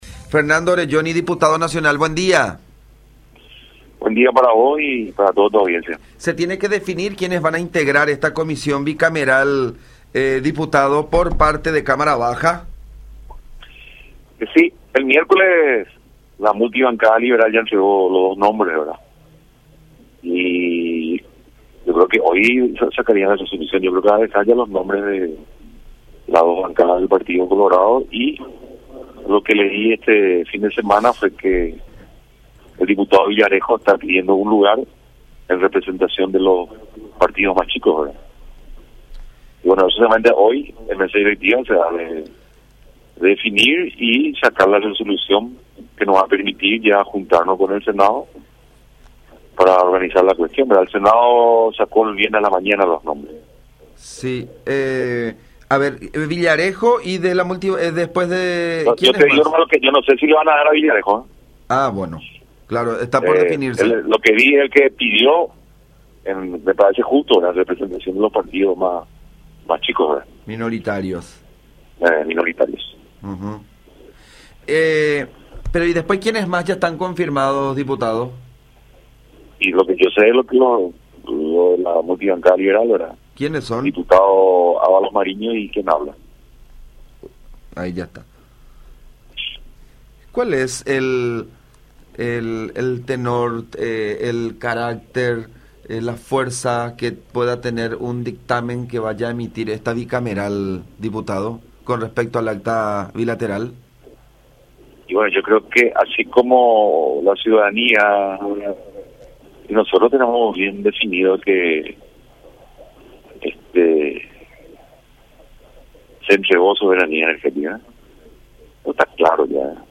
“Nosotros estamos convencidos de que fue entregada la soberanía energética. Eso está claro”, subrayó Oreggioni en contacto con La Unión.
06-Fernando-Oreggioni-Diputado-Nacional.mp3